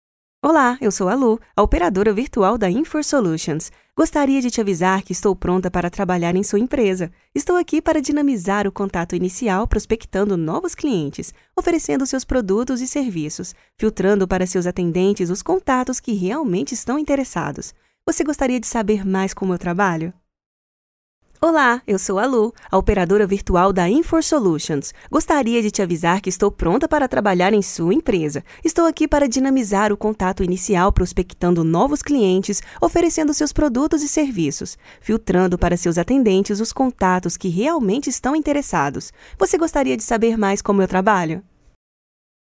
Que tal um áudio feminino para sua empresa?